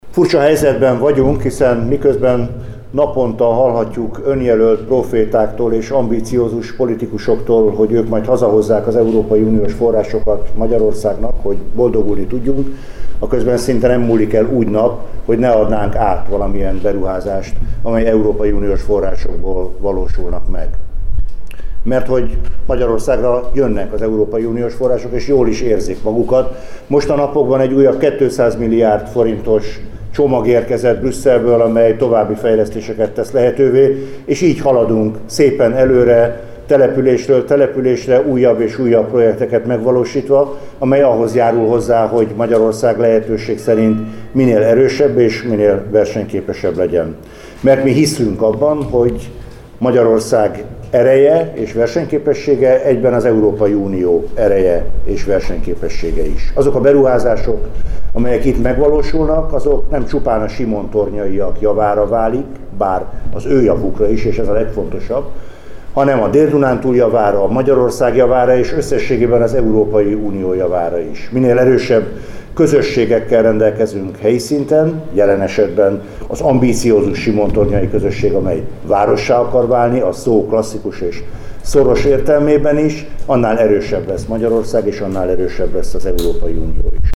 Az ünnepélyes zárórendezvényen beszédet mondott Navracsics Tibor közigazgatási és területfejlesztési miniszter is, aki elmondta, a megvalósult projektre a simontornyaiak büszkék lehetnek. A miniszter beszélt az ország Európai Uniós forrásfelhasználásairól is.